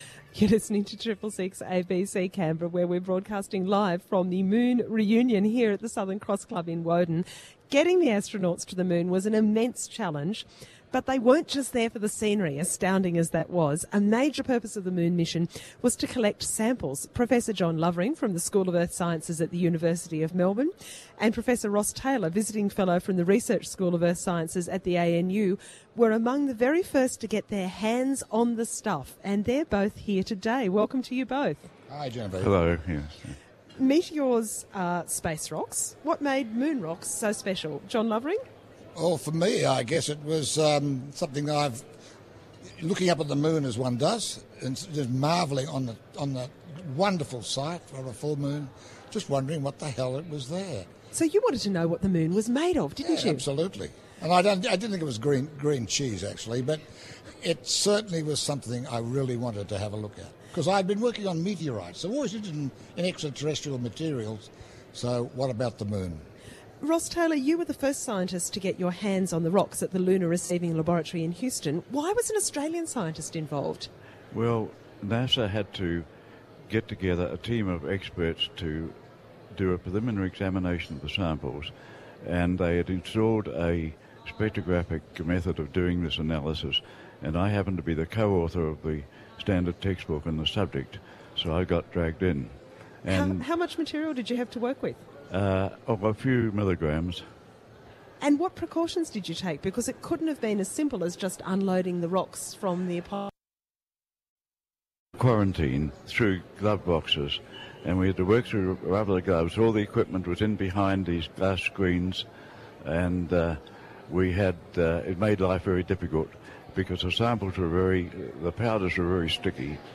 On 21 July 2009, ABC Radio 666 in Canberra broadcast live from the 40th anniversary luncheon at the Southern Cross Club’s ballroom in Woden.